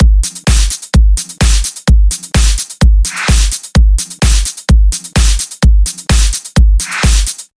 电音屋循环3
描述：另一个电音屋的鼓声循环。128 BPM
标签： 128 bpm Electro Loops Drum Loops 1.28 MB wav Key : Unknown
声道立体声